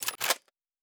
Weapon 01 Reload 2.wav